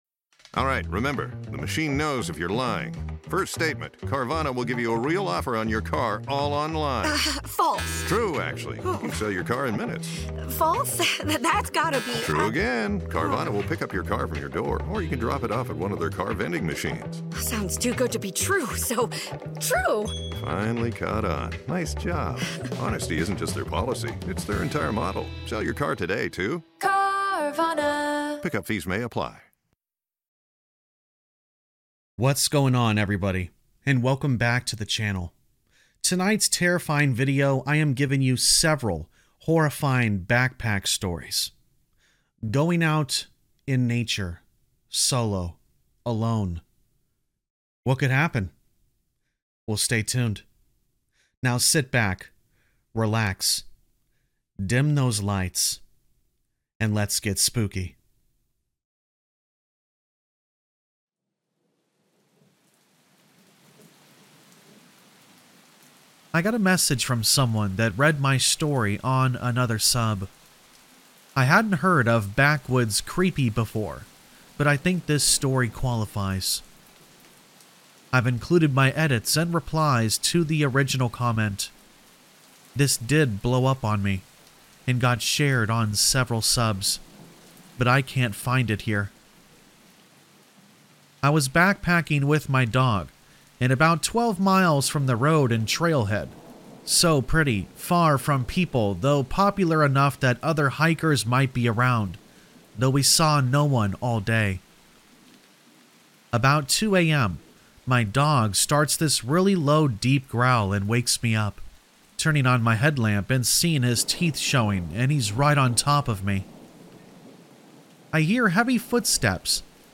6 Backpacking Horror Stories | Black Screen For Sleep | TRUE Camping & Hiking Stories | Rain Sounds